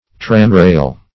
tramrail - definition of tramrail - synonyms, pronunciation, spelling from Free Dictionary
Search Result for " tramrail" : The Collaborative International Dictionary of English v.0.48: Tramrail \Tram"rail`\, n. (Mach.)